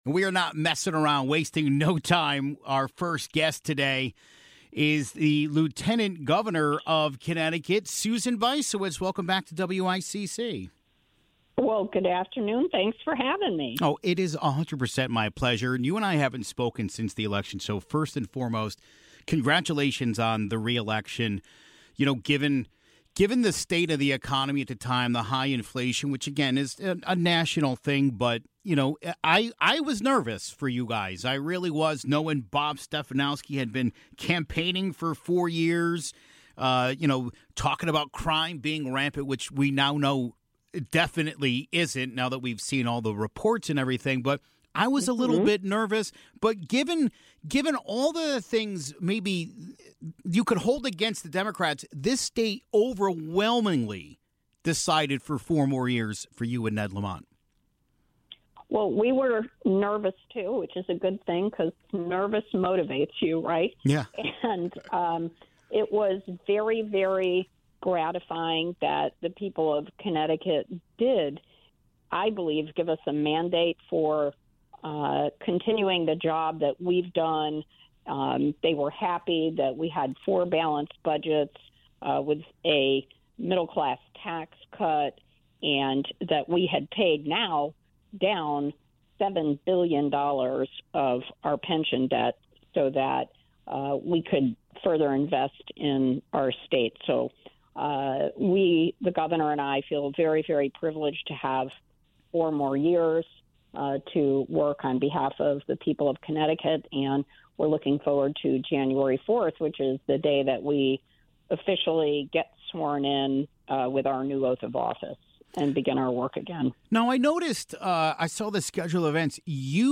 First, he welcomed Lieutenant Governor Susan Bysiewicz to talk about being reelected as Lieutenant Governor of the state as well as what their plans are (0:00).
Following her, Speaker of the House, Matt Ritter, called in to add to Bysiewicz's comments (17:26).